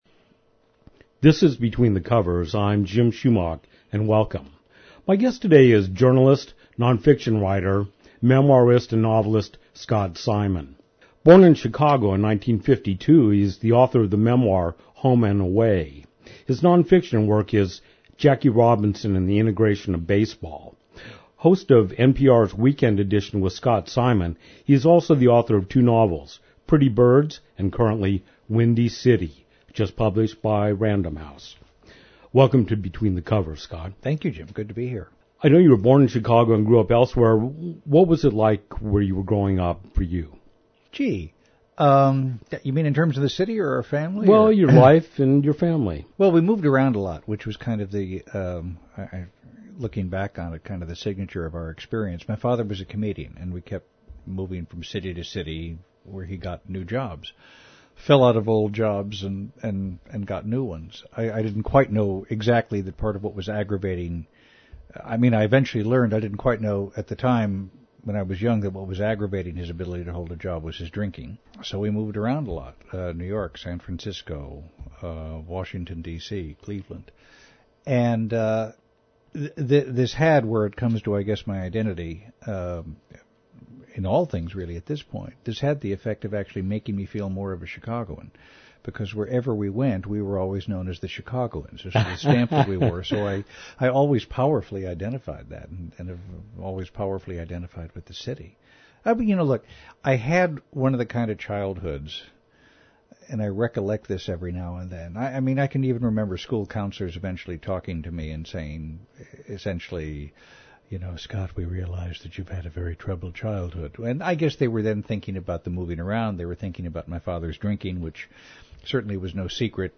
Every third Thursday from 11:00 AM to 12:00 PM A weekly show featuring interviews with locally and nationally known authors of both fiction and non-fiction.